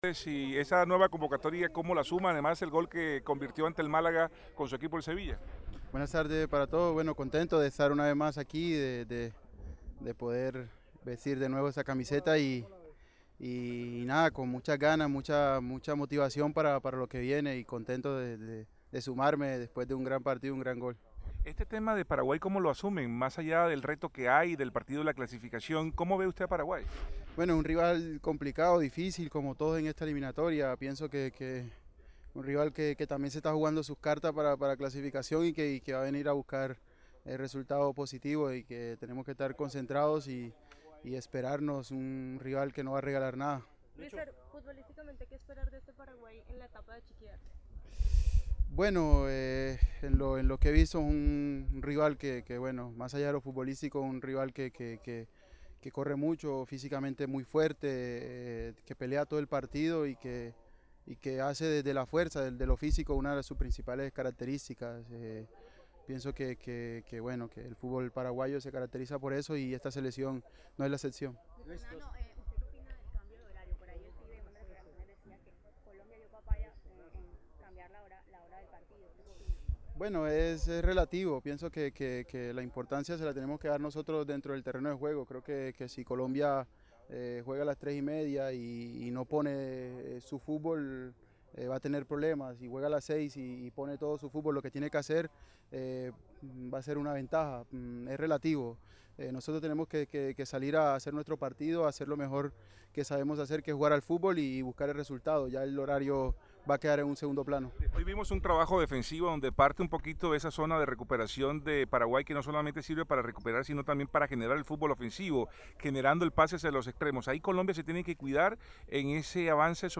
Luego del entrenamiento los jugadores David Ospina, Luis Muriel y Dávinson Sánchez atendieron a la prensa para hablar sobre el próximo compromiso, analizar al rival y el presente del equipo nacional.